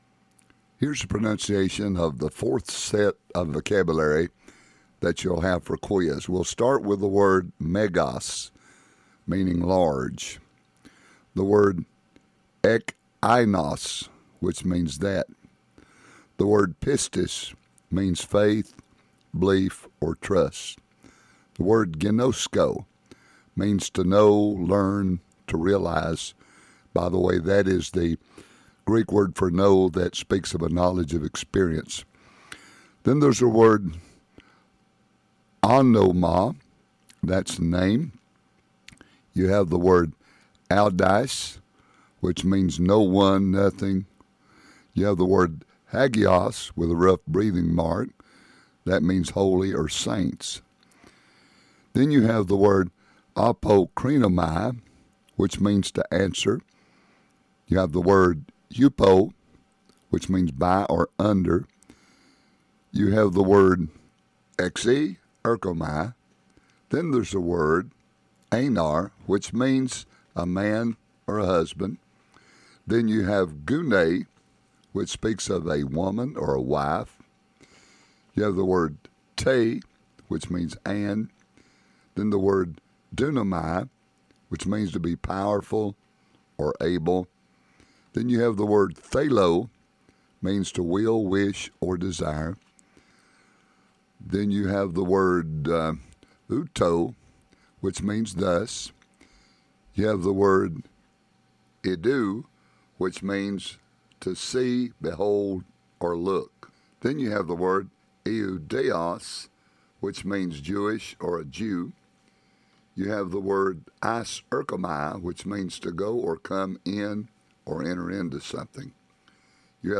Click on this line to hear pronunciations for Wk 6 quiz